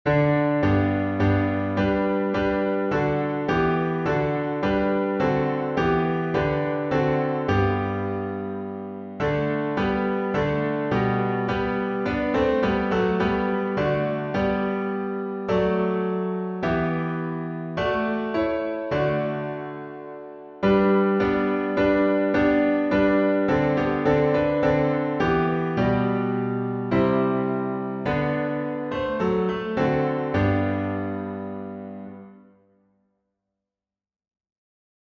Alternate harmonies